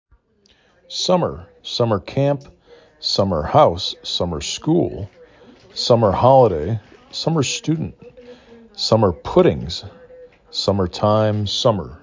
sum mer
s uh m er